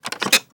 snd_ui_box.wav